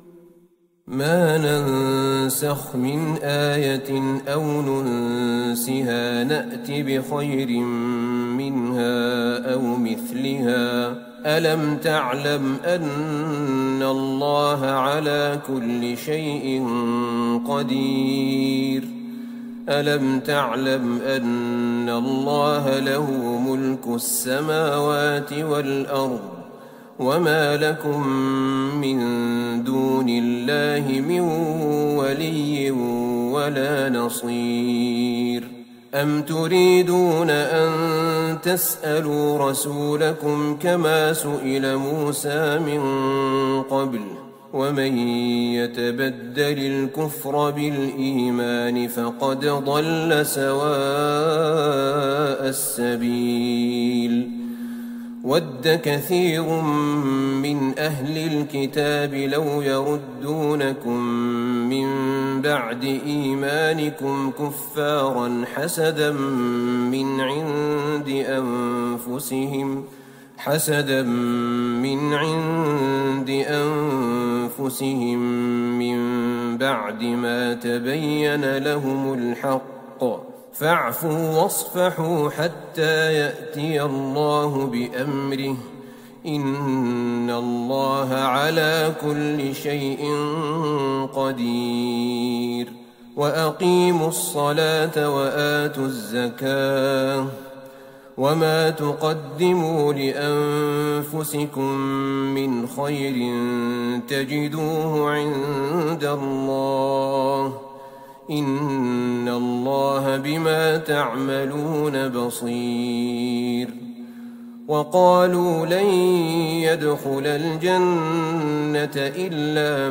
ليلة ٢ رمضان ١٤٤١هـ من سورة البقرة {١٠٦-١٦٧} > تراويح الحرم النبوي عام 1441 🕌 > التراويح - تلاوات الحرمين